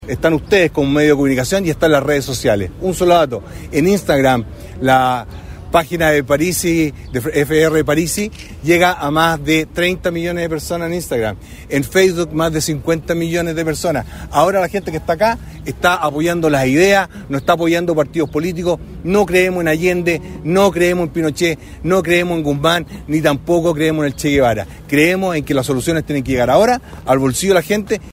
Desde la Plaza Independencia, en el centro de Concepción, y rodeado por alrededor de un centenar de adherentes, el postulante del Partido de la Gente (PDG) presentó algunas de sus propuestas, entre las que figura la baja de sueldos en la administración del estado, la devolución del IVA en los medicamentos y el término de las devoluciones de gasto electoral.